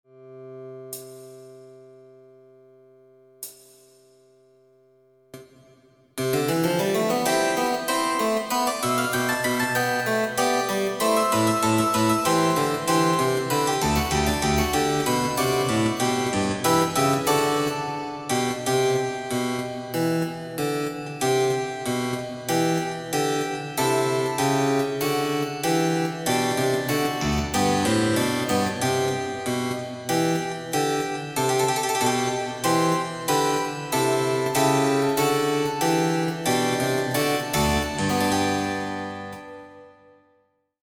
（ただし、音はリコーダーの音域すべてが含まれますので、まだおぼえていない音があるかたは、知らない音については、指使い表などを参照して指使いをおぼえてから挑戦してください。）
（ピッチはすべてモダンピッチ。ただし「正解」のほうには奏者が自由につけたトリルなどが入っているところがあります。）
課題　１　伴奏スタート！